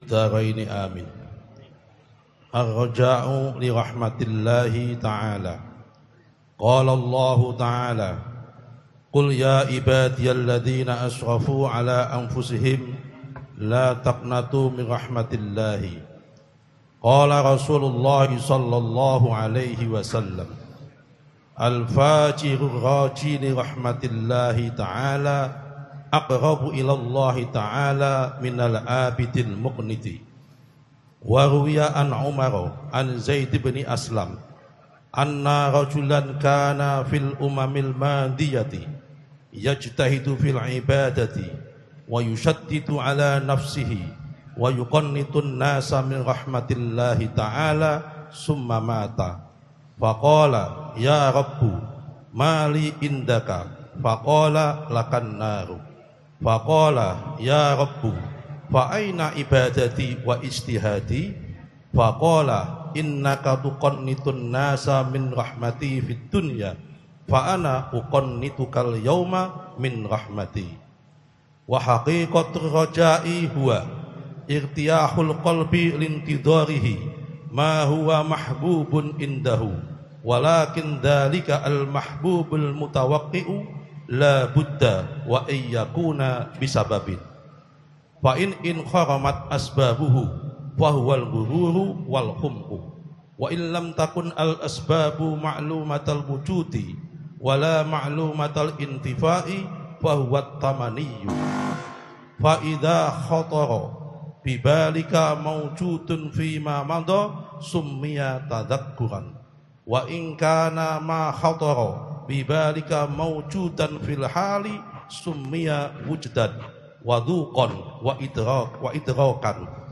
الدرس الخامس للعلامة الحبيب عمر بن حفيظ في شرح كتاب: قامع الطغيان على منظومة شعب الإيمان، للعلامة محمد نووي بن عمر البنتني الجاوي، في مسجد الا